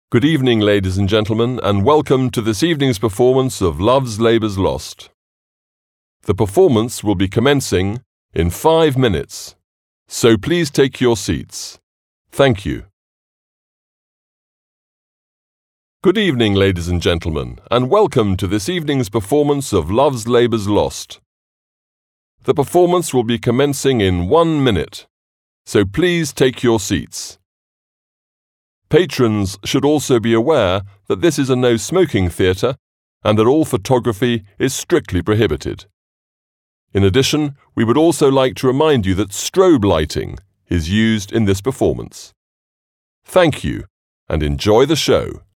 THEATRE ANNOUNCEMENT
THEATRE-ANNOUNCING-1.mp3